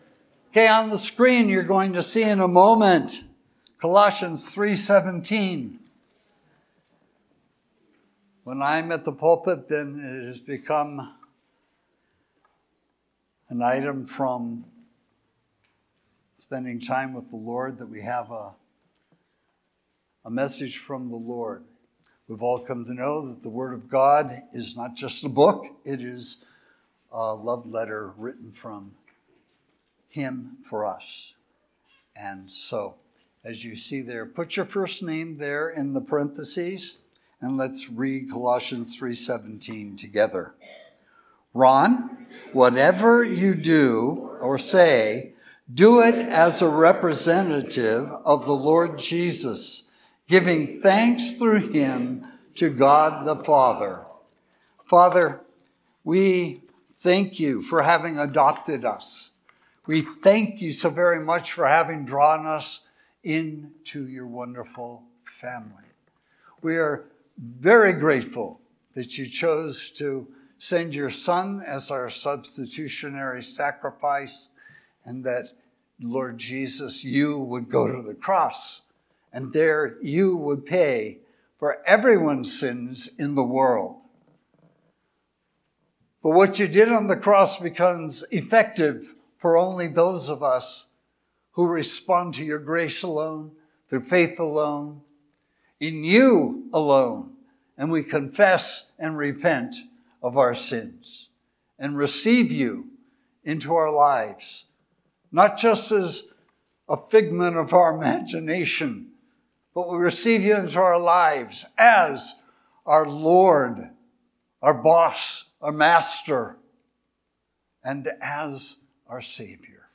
Sermons – Page 6 – Granada Hills Community Church